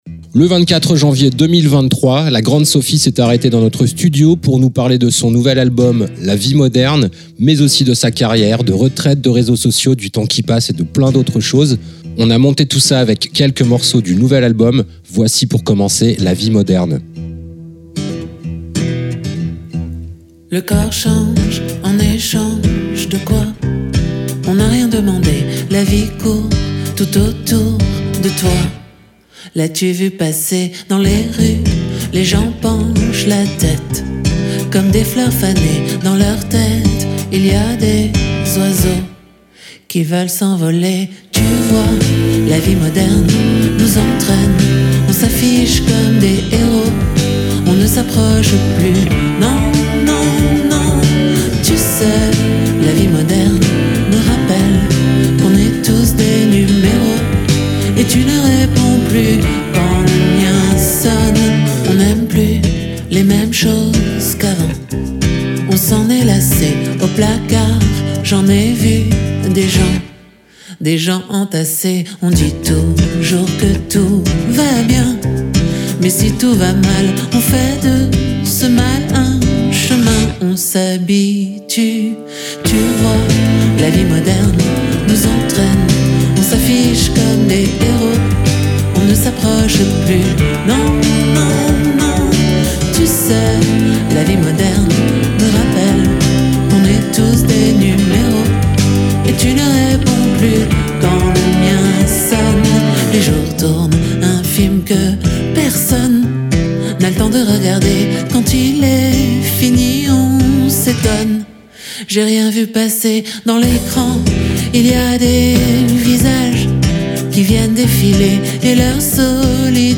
La Grande Sophie / conversation autour du nouvel album "la vie moderne"
le 24 janvier 2023 La Grande Sophie s'est arretée dans notre studio pour nous parler de son nouvel album